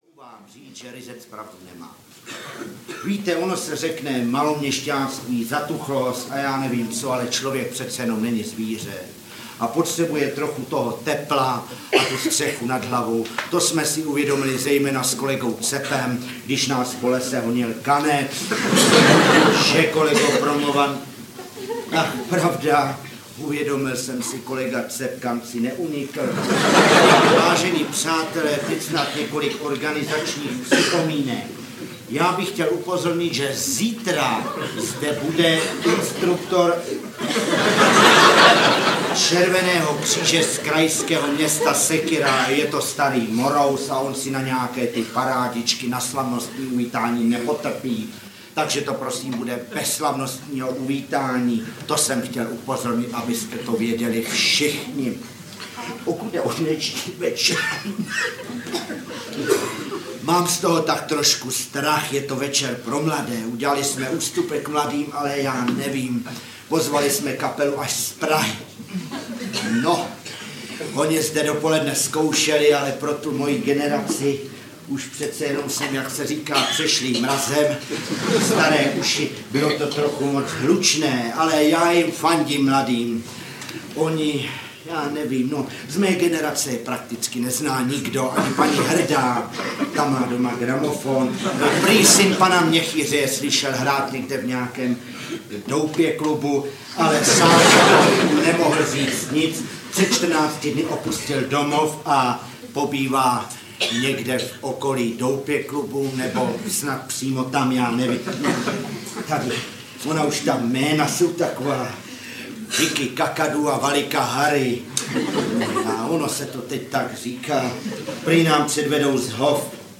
Ukázka z knihy
Na deseti CD se můžeme přesvědčit, že Šimek a Sobota byli ve své době respektováni jako nositelé originálního a nápaditého humoru a jako takoví účinkovali nejen v divadle Semafor, ale objevovali se i v různých televizních pořadech a na gramofonových deskách.